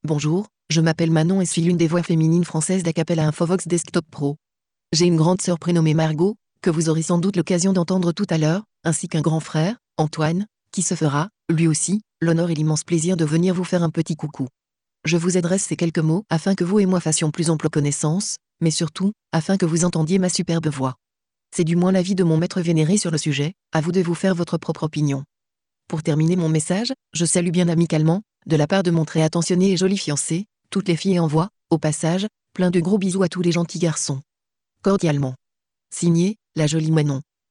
Texte de démonstration lu par Manon, voix féminine française d'Acapela Infovox Desktop Pro
Écouter la démonstration de Manon, voix féminine française d'Acapela Infovox Desktop Pro